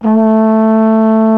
TENORHRN A 1.wav